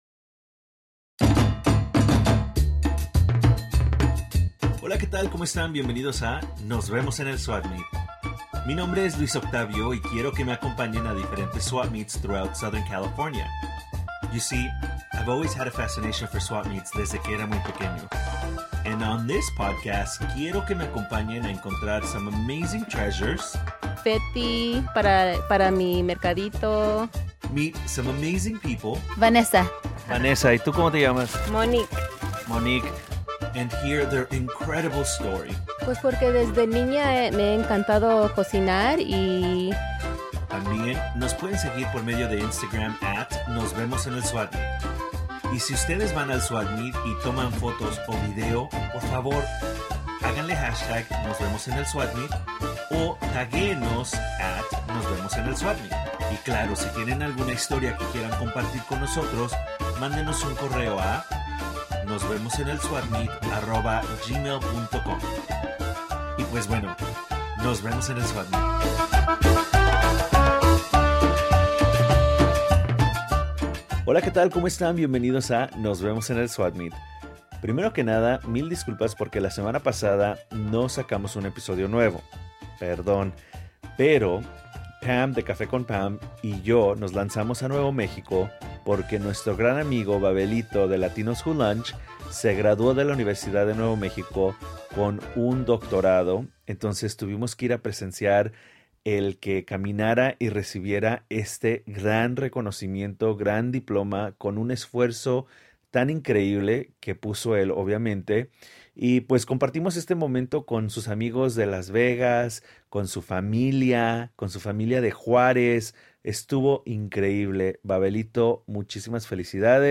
• La Primer Parada fue para comer Tacos De Birria en la esquina de La 4ta y Niños Héroes • Segunda Parada Mercado Hidalgo • Tercer Parada Mercado de artesanías • Cuarta Parada La Corriente NAIS • Quinta Parada Plaza Rio